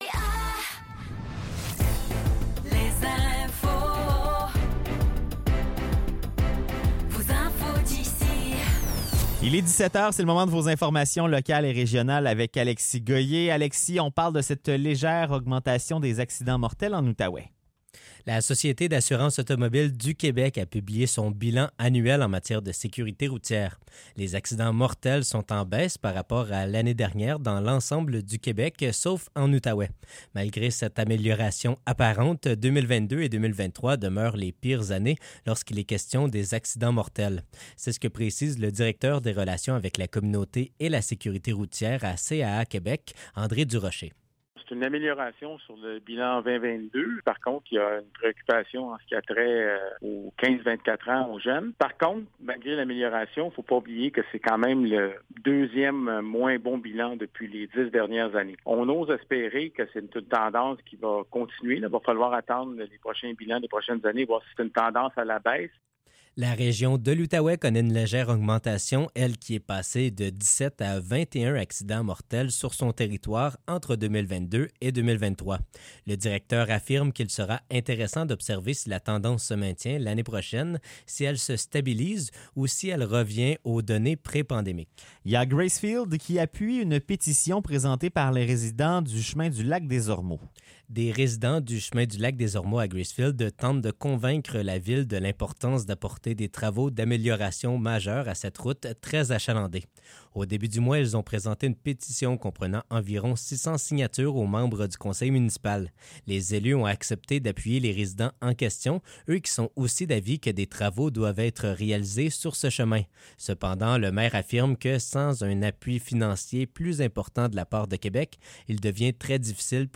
Nouvelles locales - 12 juin 2024 - 17 h